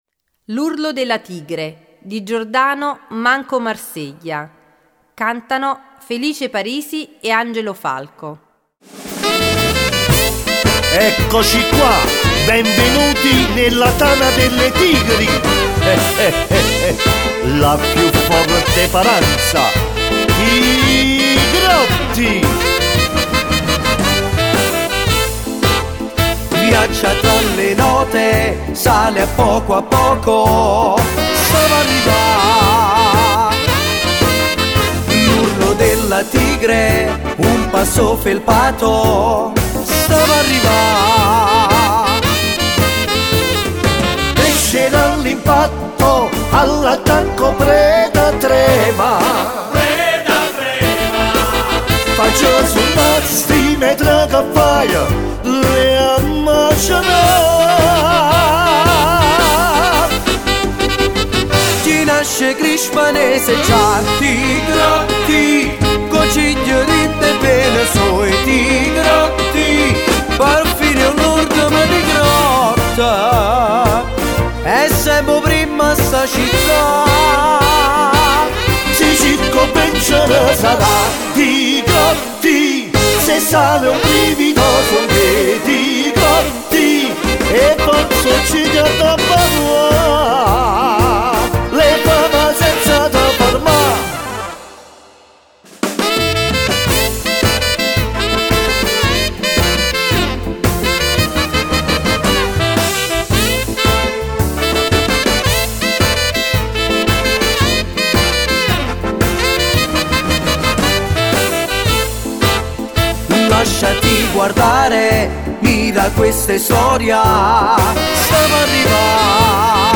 Cantanti